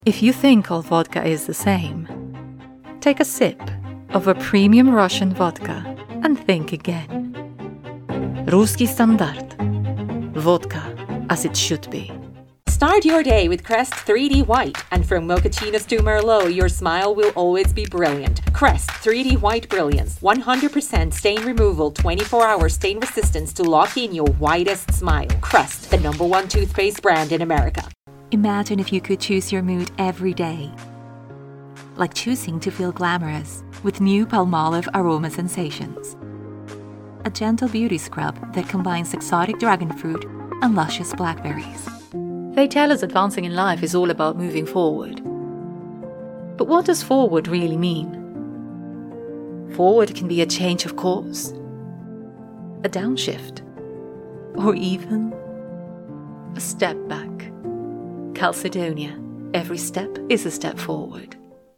I am a British female voice actor from London with a neutral RP accent. My voice has been described as: calm, educated, clear, reassuring, authentic, believable, warm, conversational and friendly....